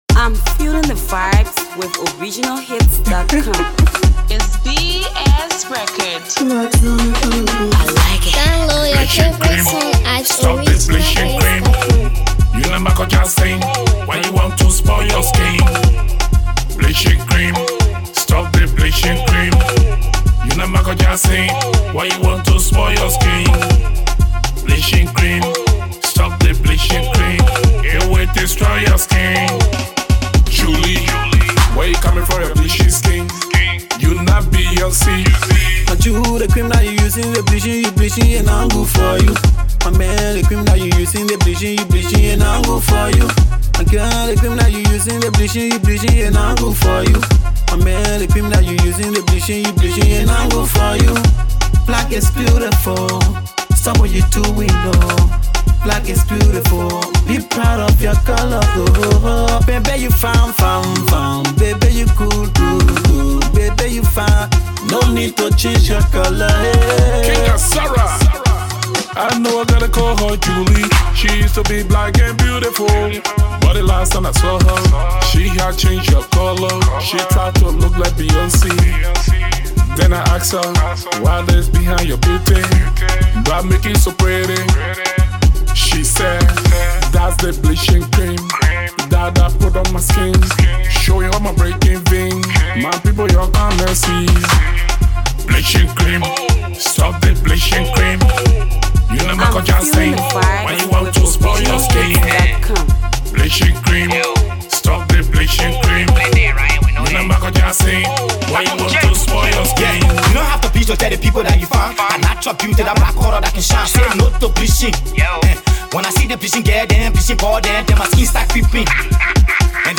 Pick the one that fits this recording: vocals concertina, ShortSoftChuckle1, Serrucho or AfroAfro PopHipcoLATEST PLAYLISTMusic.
AfroAfro PopHipcoLATEST PLAYLISTMusic